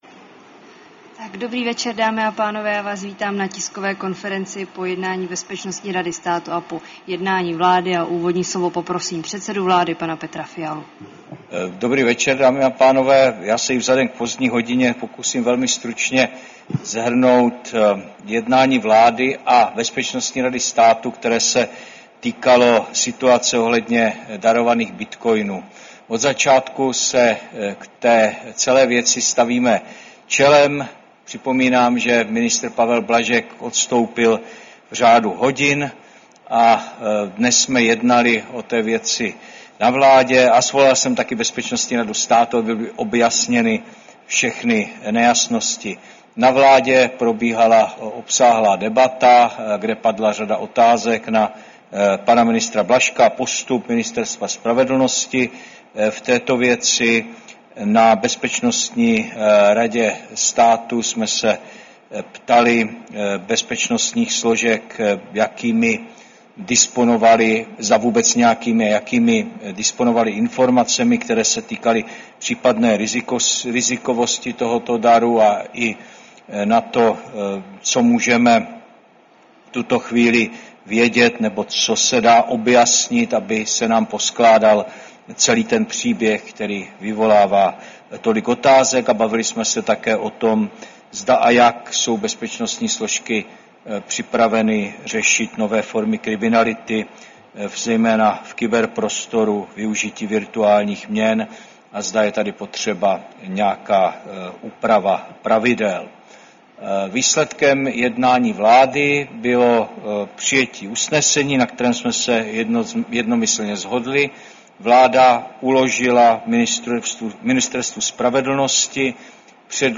Tisková konference po jednání vlády, 4. června 2025